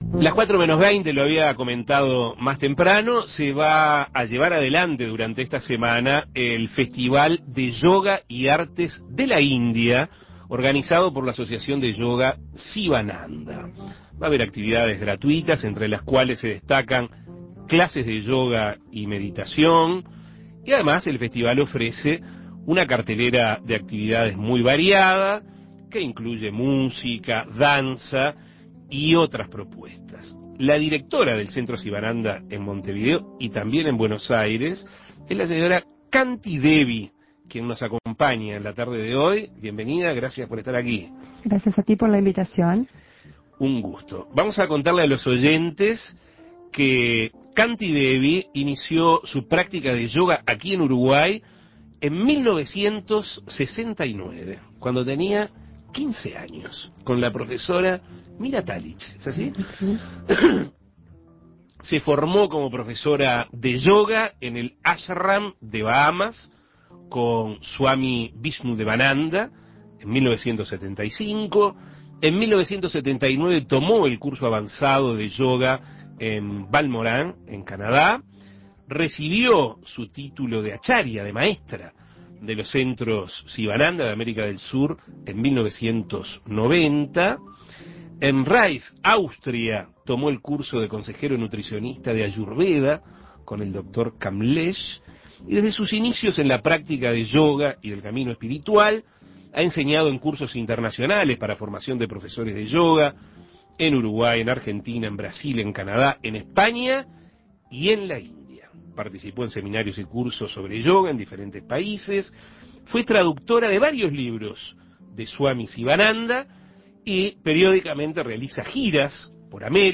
Entrevistas Festival de yoga Festival de yoga Festival de yoga Festival de yoga Imprimir A- A A+ Esta semana se lleva a cabo el Festival de Yoga y Artes de la India, organizado por el Centro Sivananda.